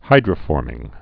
(hīdrə-fôrmĭng)